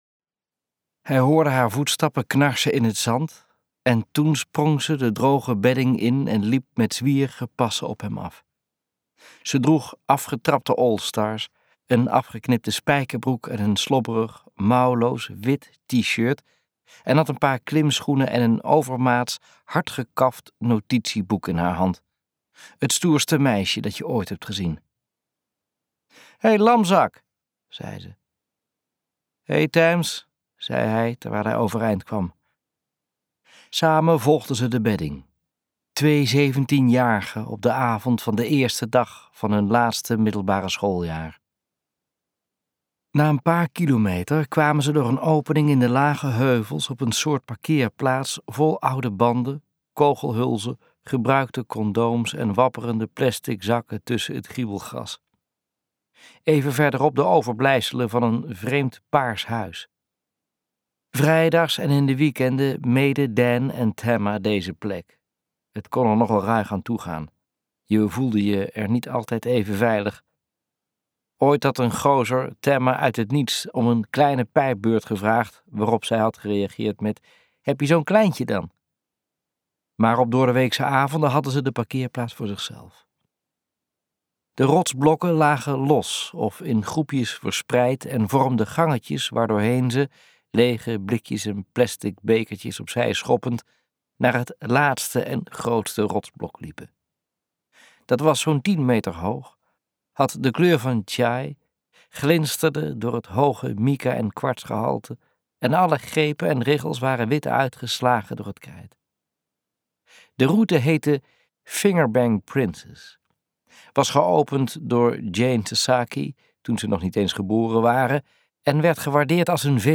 Kantelpunt luisterboek | Ambo|Anthos Uitgevers